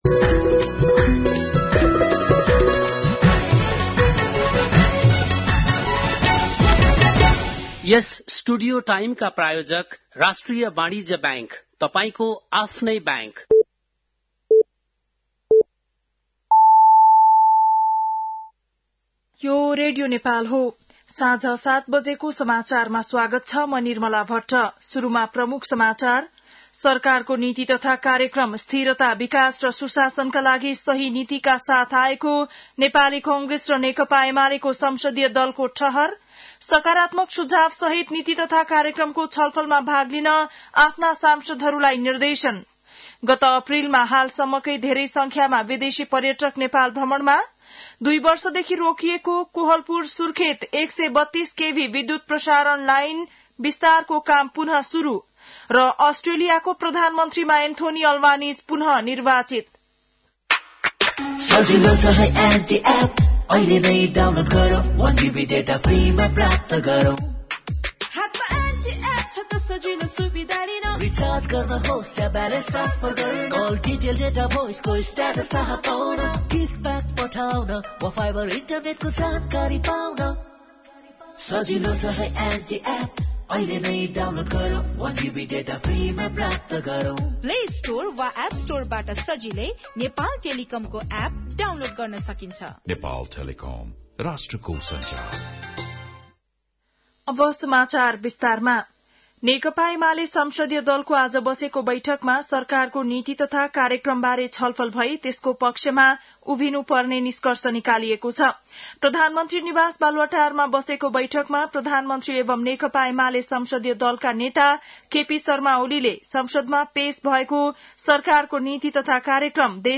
बेलुकी ७ बजेको नेपाली समाचार : २० वैशाख , २०८२